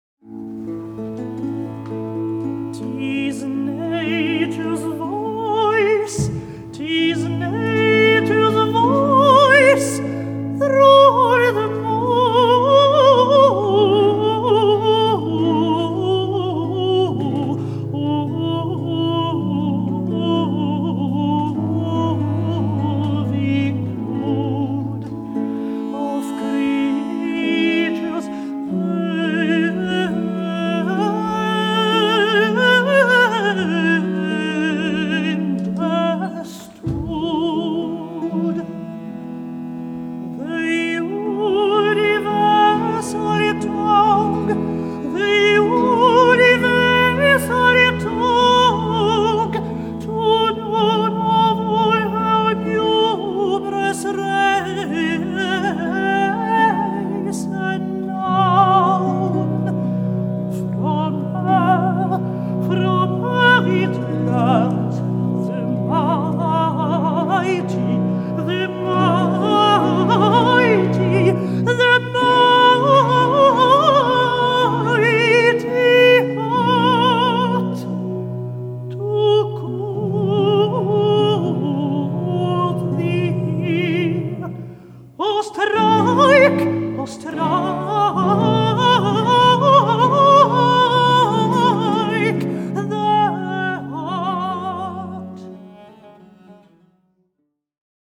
Philippe Jaroussky & Ensemble ArtaserseMusic by Henry Purcell
Recorded live at Stockholm Early Music festival, German Church, Stockholm June 2010.
baroque violin
flute
viola da Gamba
theorbo
harpsichord